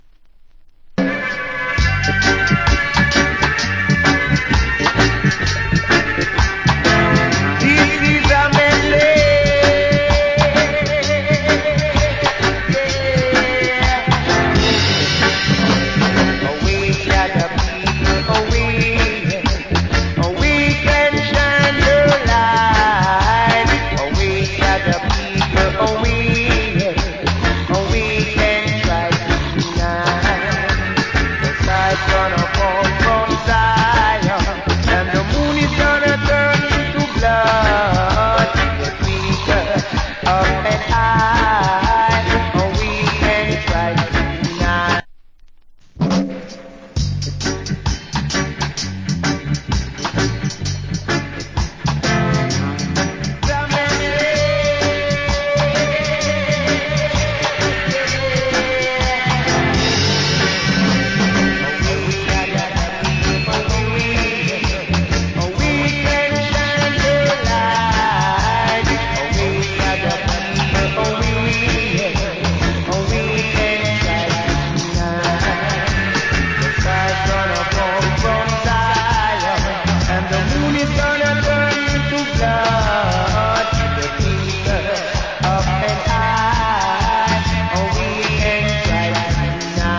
Killer Roots Vocal.